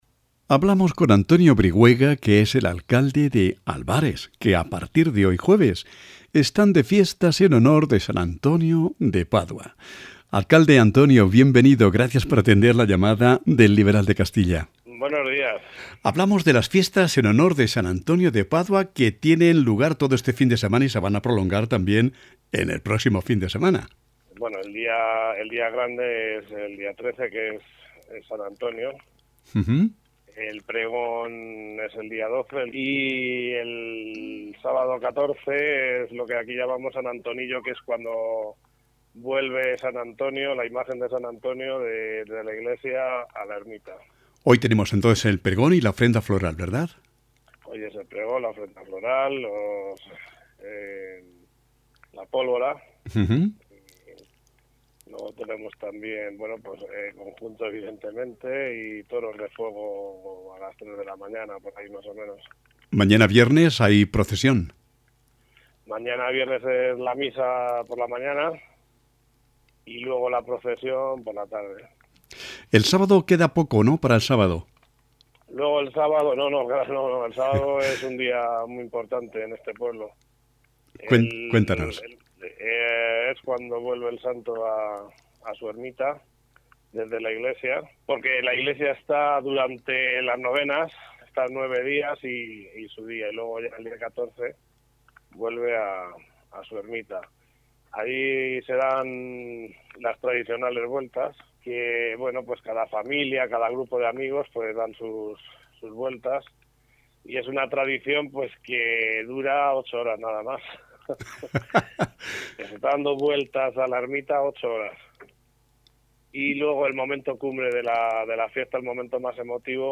Hablamos con Antonio Brihuega, alcalde de Albares sobre las fiestas de san Antonio, la iglesia y las chozas | Informaciones de Guadalajara